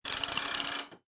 telephone_handle2.mp3